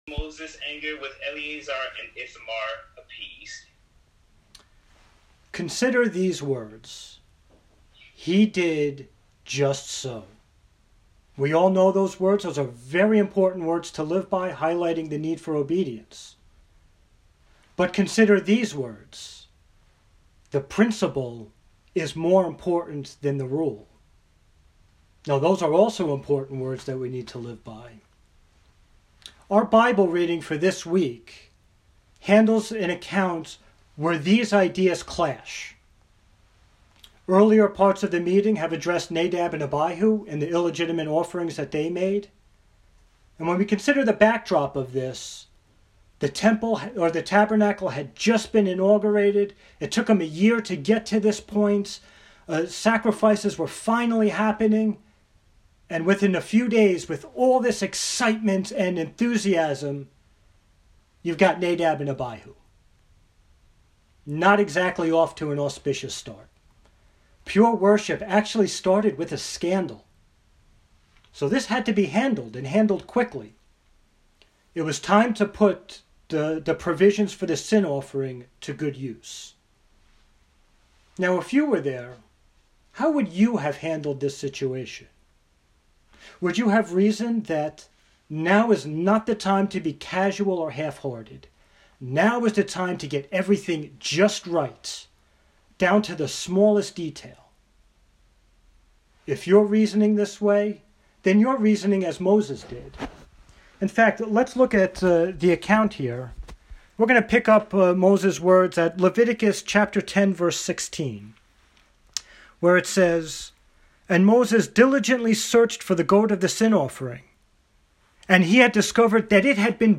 Given at home over Zoom during the Covid years